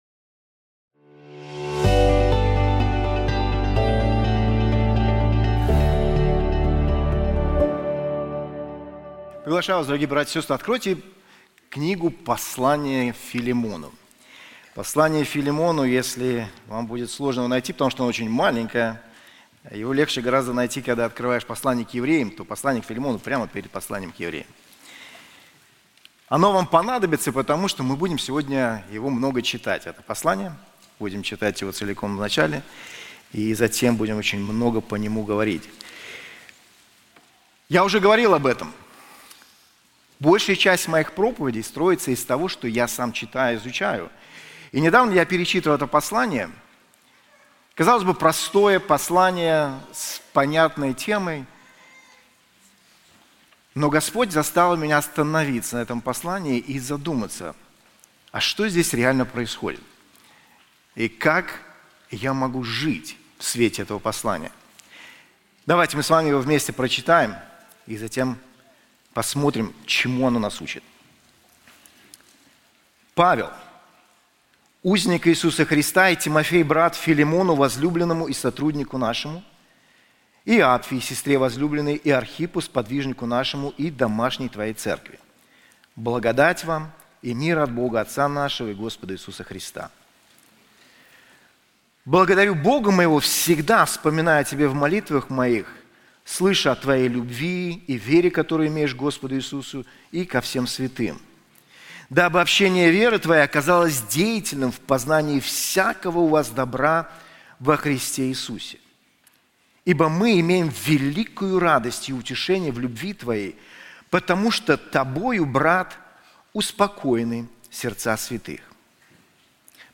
This sermon is also available in English:Injustice and My Christianity • Epistle to Philemon